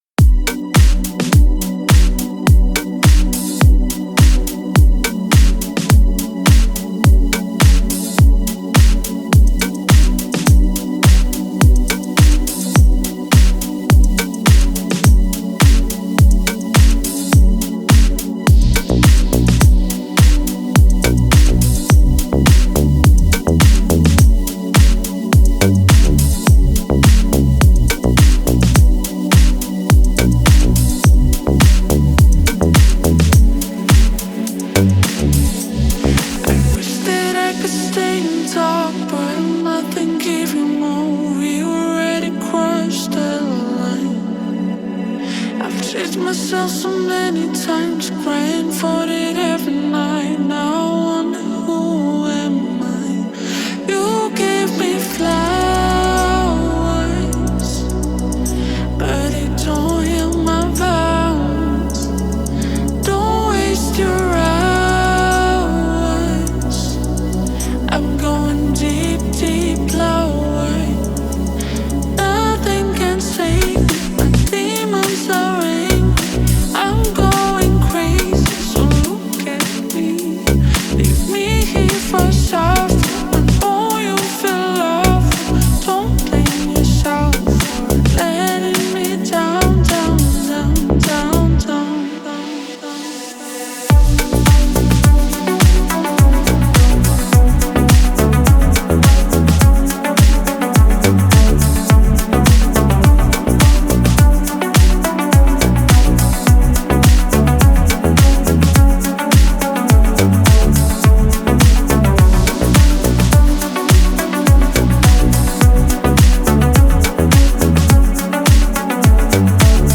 Жанр: Deep House